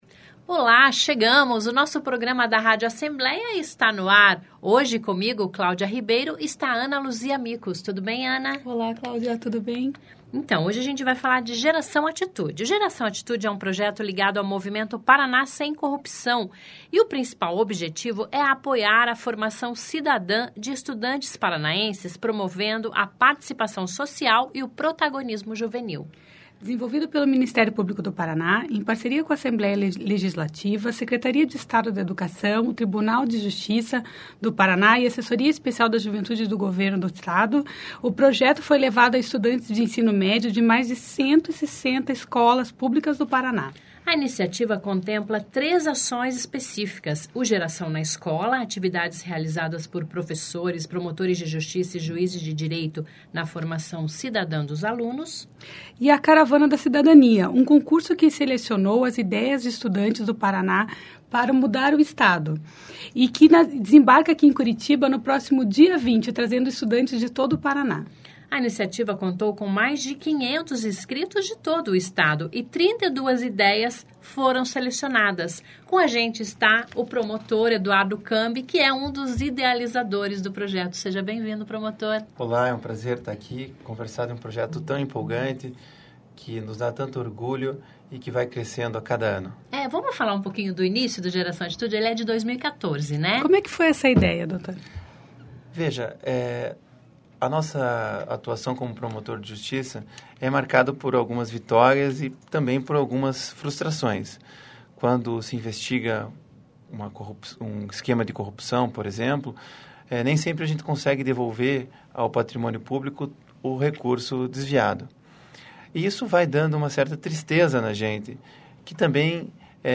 Confira entrevista com o promotor.